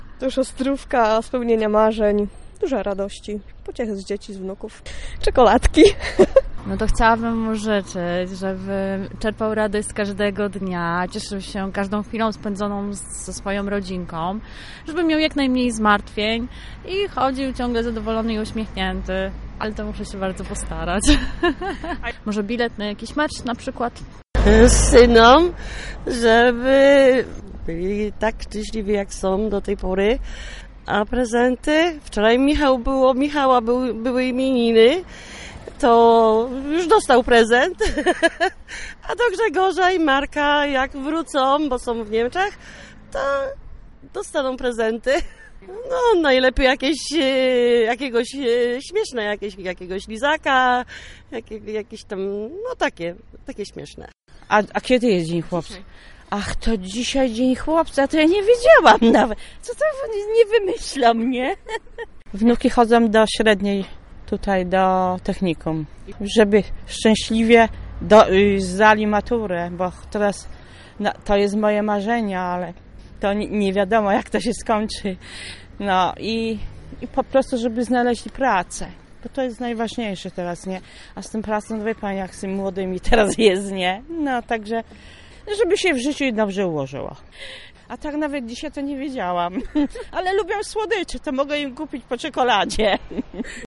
Takie swoim chłopcom będą składały mieszkanki Pałuk. Powiedziały też jakie prezenty dla nich przygotowały.